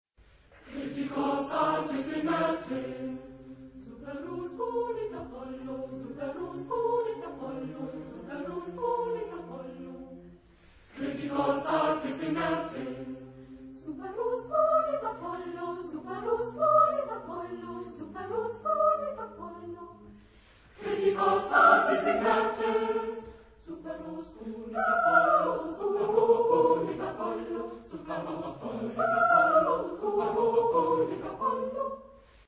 Zeitepoche: 20. Jh.
Genre-Stil-Form: Zyklus ; weltlich
Chorgattung: SATB  (4 gemischter Chor Stimmen )